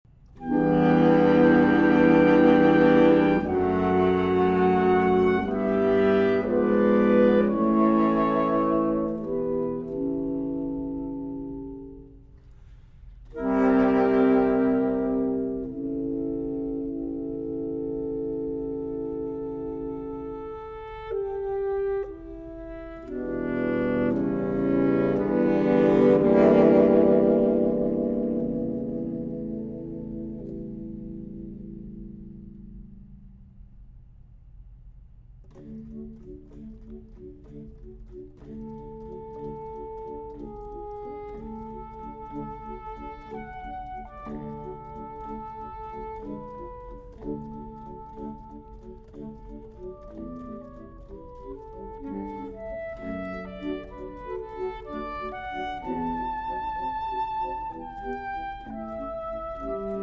Saxophone Ensemble
Soprano Saxophone
Alto Saxophone
Tenor Saxophone
Baritone Saxophone